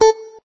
note_beepey_6.ogg